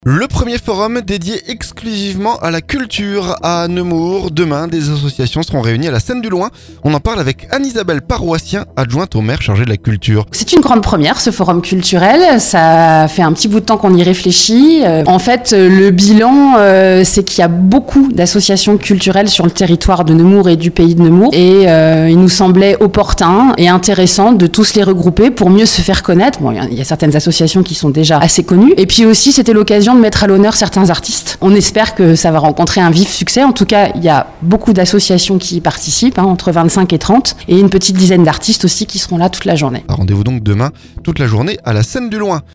Le premier forum dédié exclusivement à la culture à Nemours. Samedi, des associations seront réunies à la scène du loing. On en parle avec Anne-Isabelle Paroissien adjointe au maire chargée de la culture